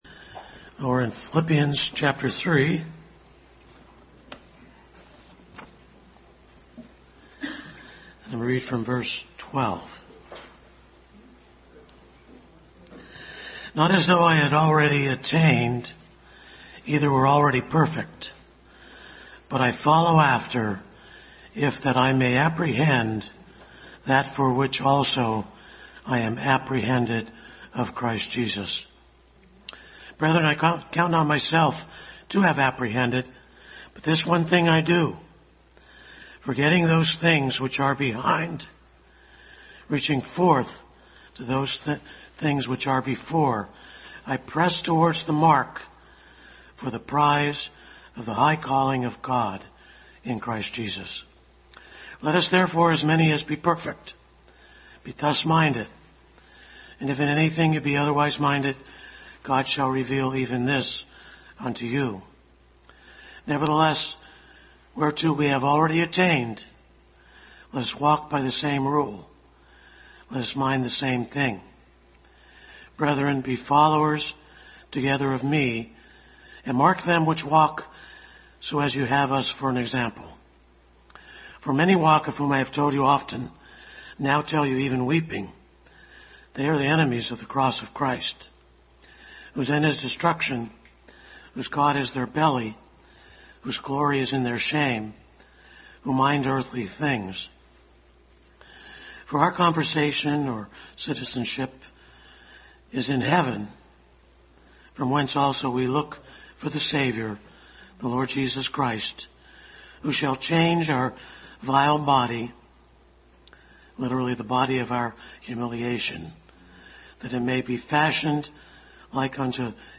Adult Sunday School continued study in Philippians.
1 Corinthians 15 Service Type: Sunday School Adult Sunday School continued study in Philippians.